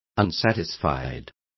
Complete with pronunciation of the translation of unsatisfied.